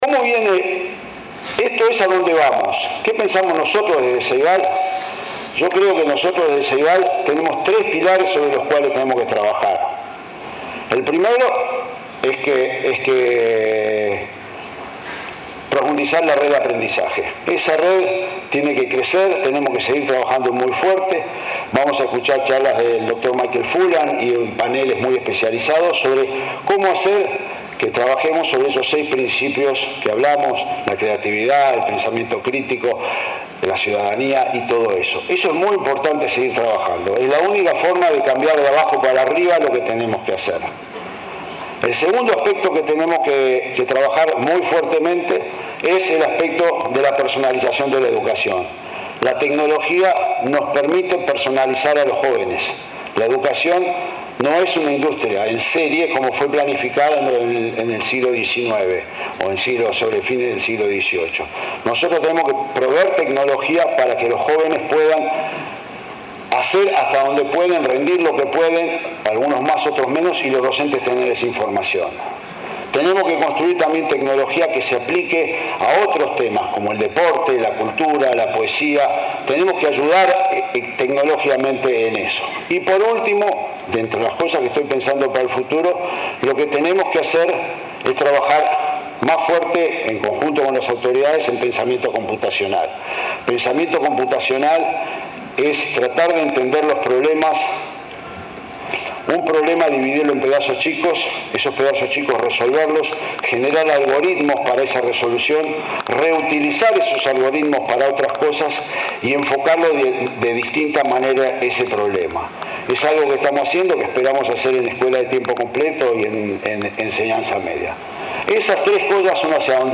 El director del Plan Ceibal, Miguel Brechner, sostuvo, durante el Foro Internacional de Educación y Tecnología que se realizó en el LATU, que el Ceibal trabajará en tres pilares: profundizar la red de aprendizajes, personalizar la educación y acentuar el pensamiento computacional.